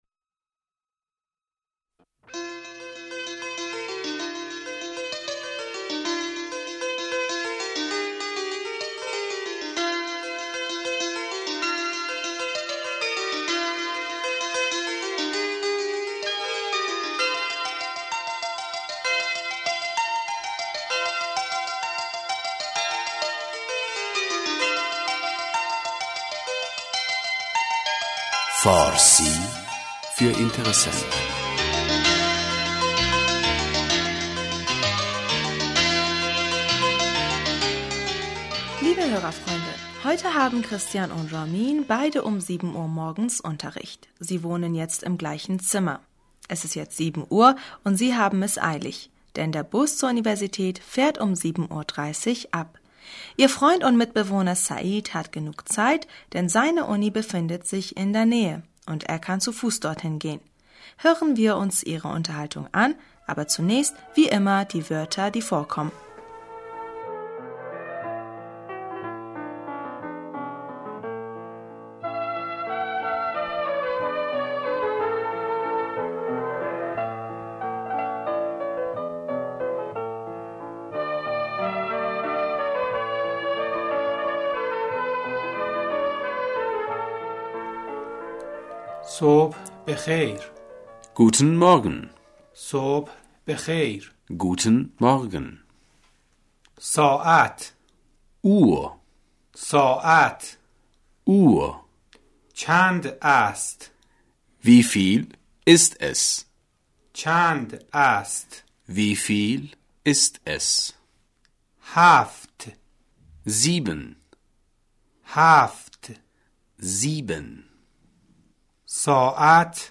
Moderatorin: Hören Sie nun bitte das Gespräch: